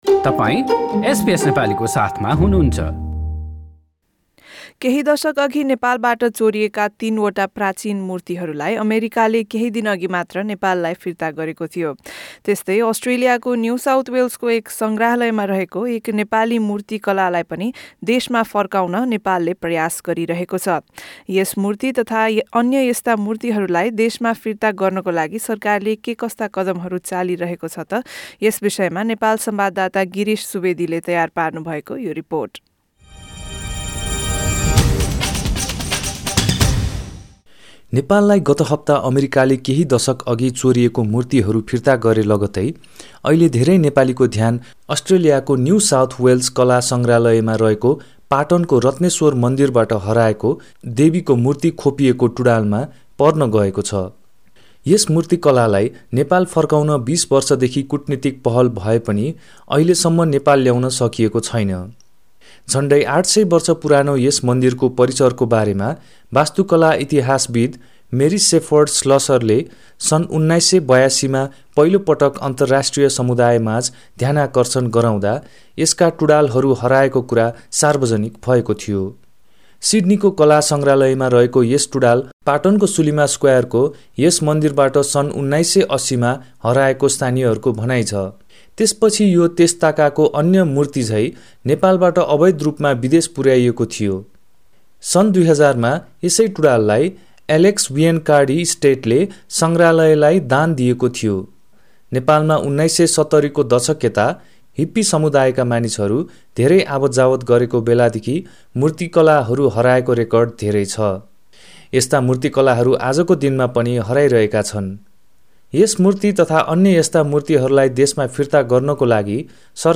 SBS Nepali